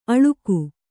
♪ aḷuku